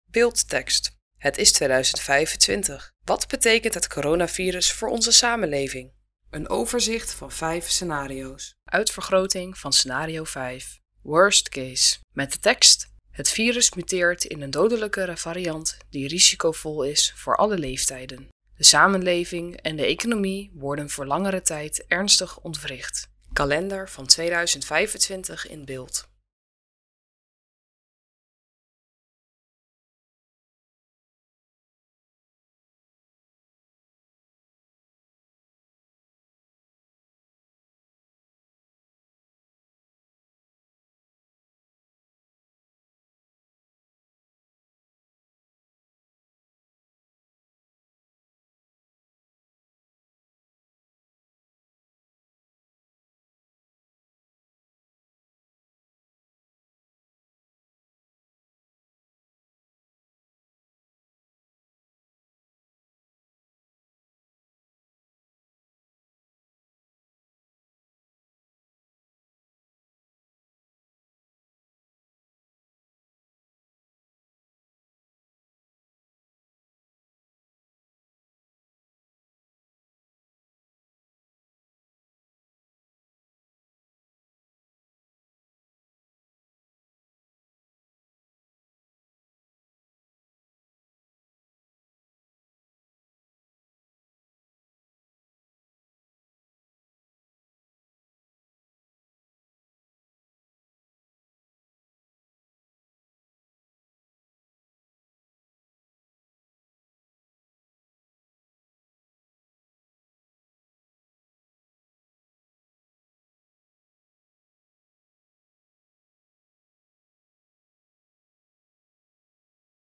Rustige muziek speelt
Lo-fi muziek speelt